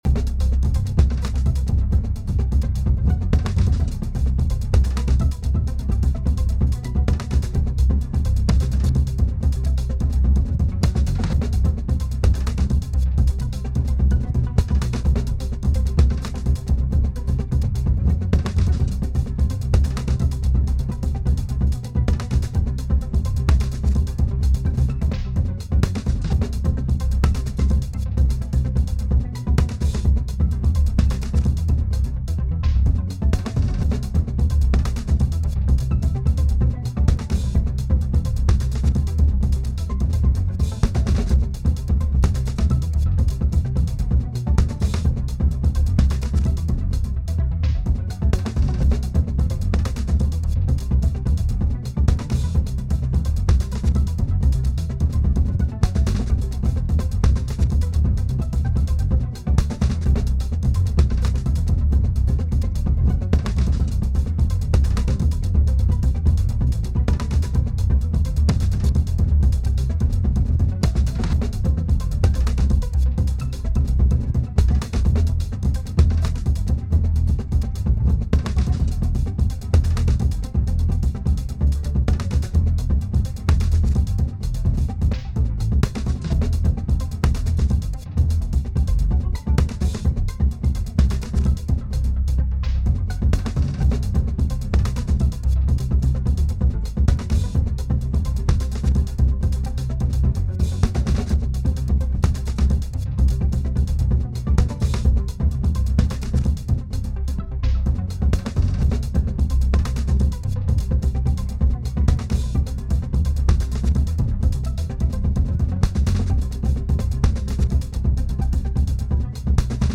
🌪 Strange chopped polyrythmic (breakbeats 128bpm)